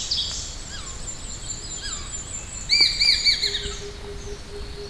japanese-sparrowhawk-wuying-heilongjiang-china.wav